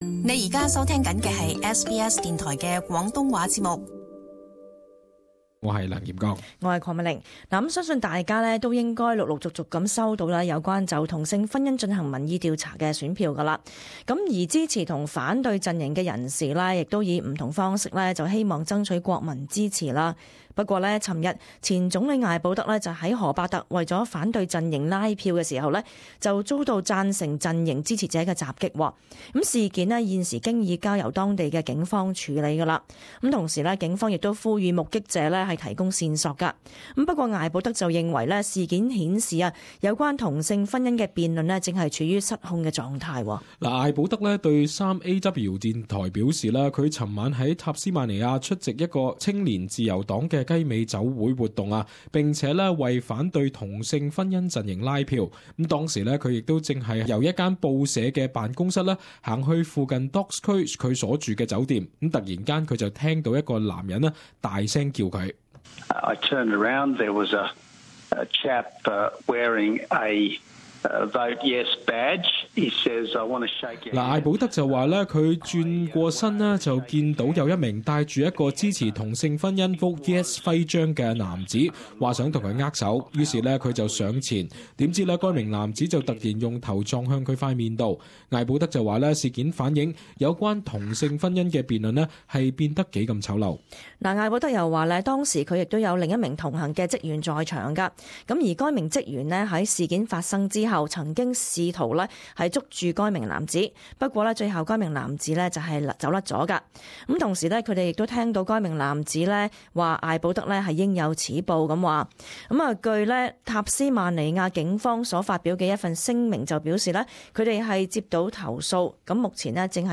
时事报导